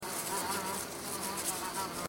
insects-flies-buzzing-01.ogg